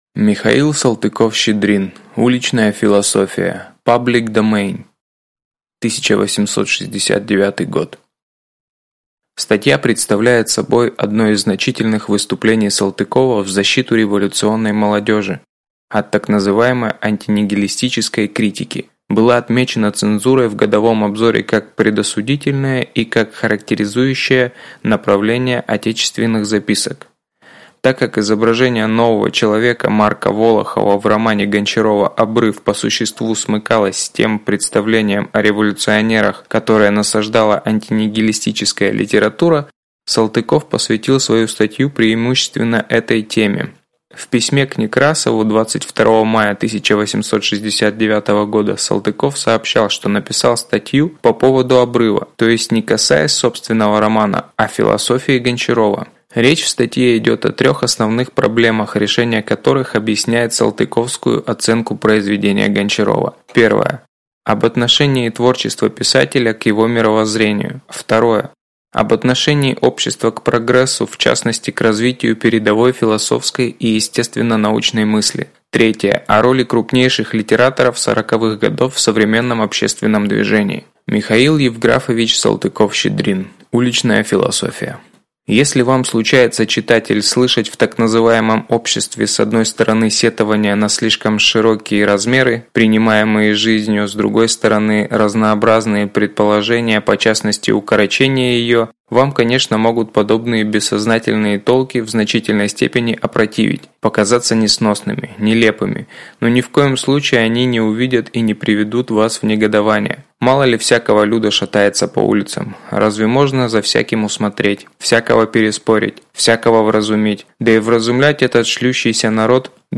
Аудиокнига Уличная философия | Библиотека аудиокниг